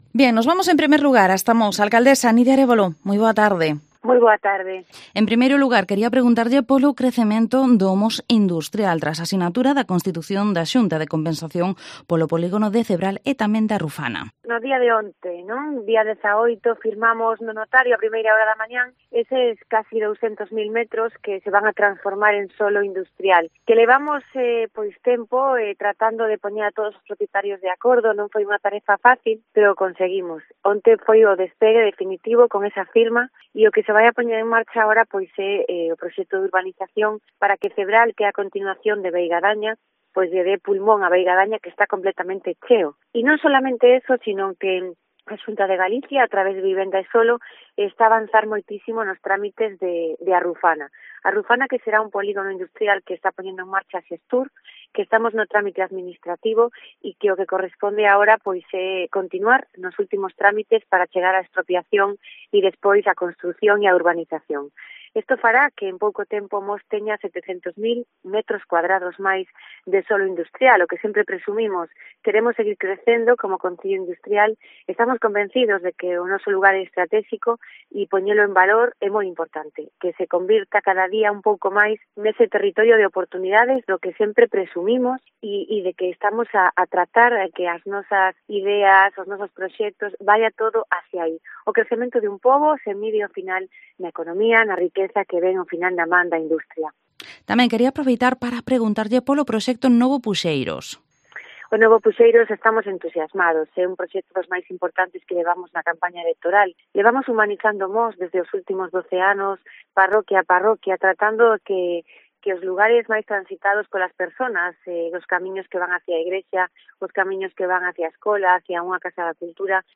Entrevista a la Alcaldesa de Mos, Nidia Arévalo